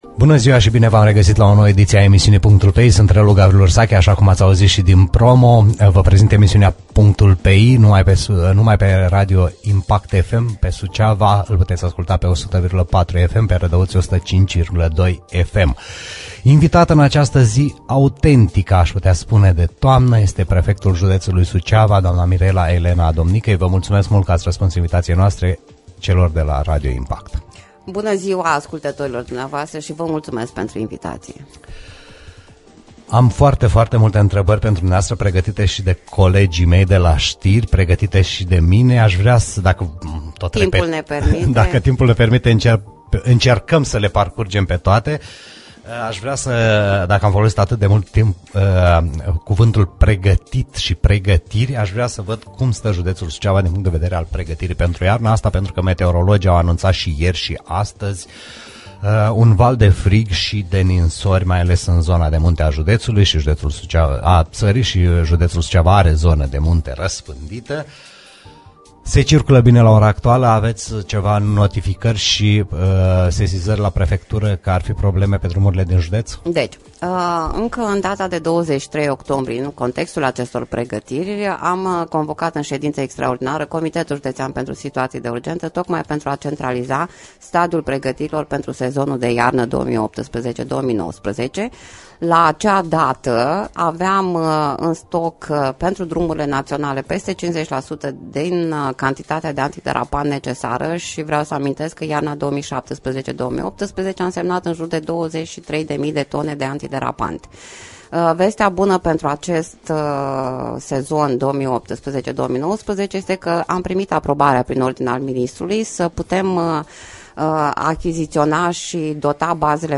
Prefectul Mirela Adomnicăi live la PUNCTUL PE I